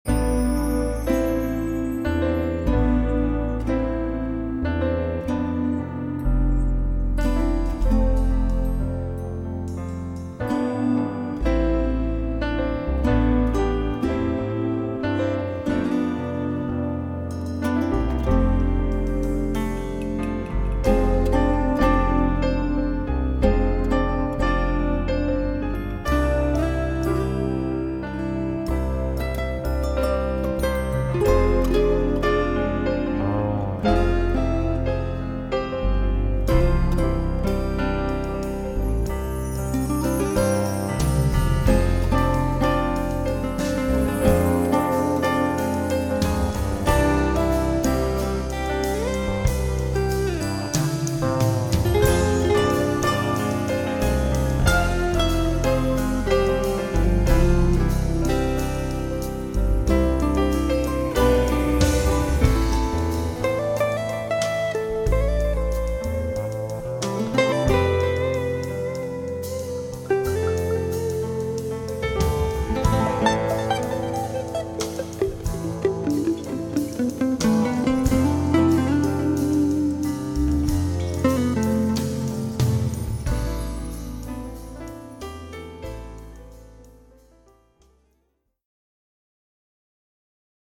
ヒーリングＣＤ